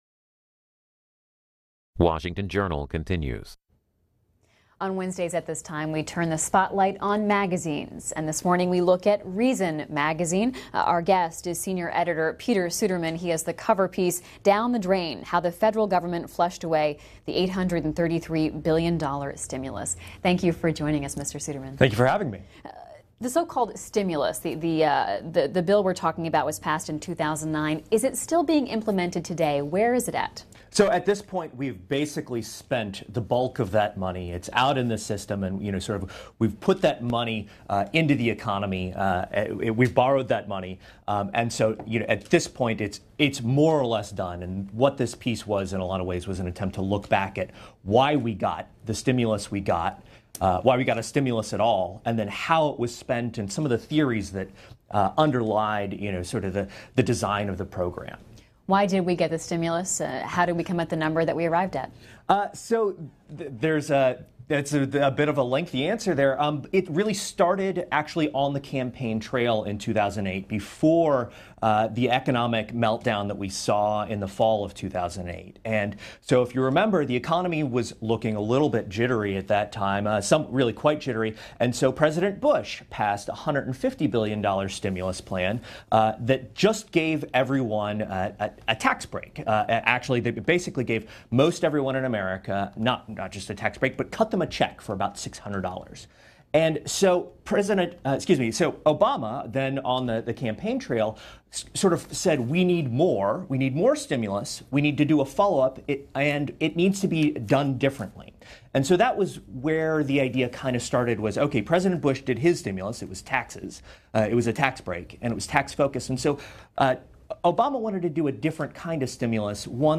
appeared on C-SPAN's Washington Journal